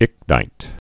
(ĭknīt) also ich·no·lite (-nō-līt)